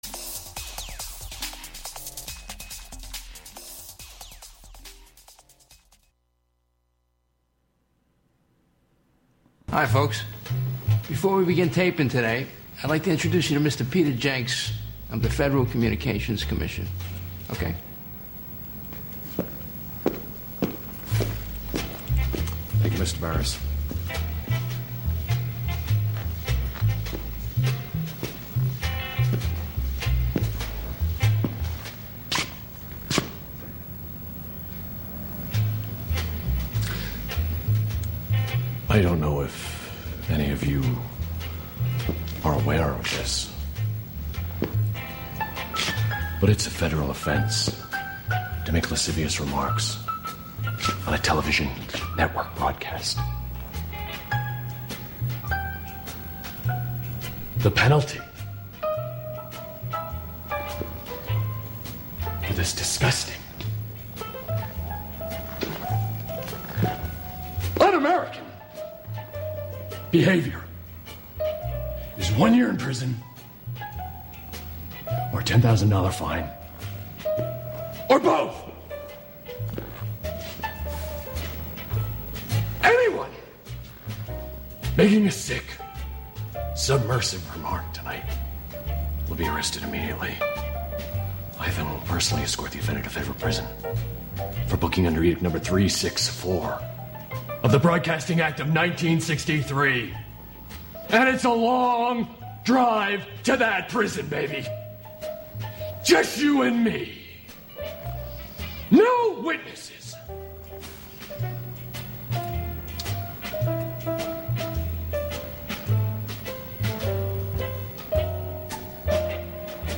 An upbeat music show featuring the American songbook
in a diverse range of genres live